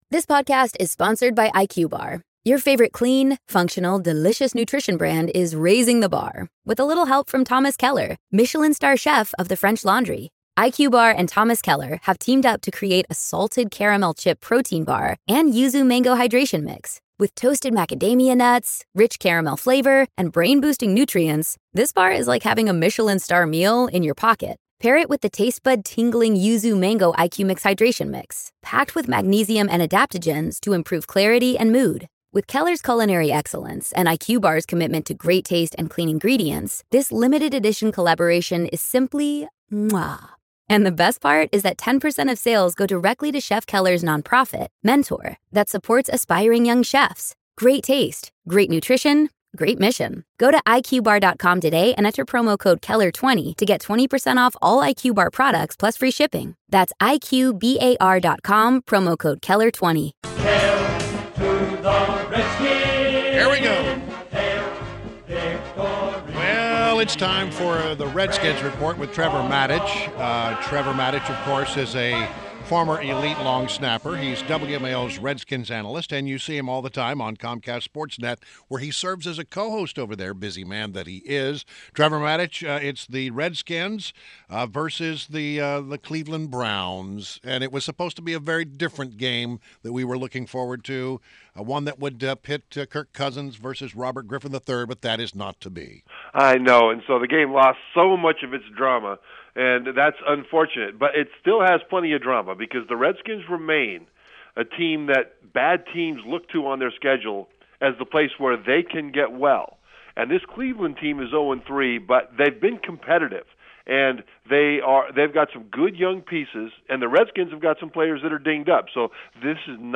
WMAL Interview - TREVOR MATICH - 09.30.16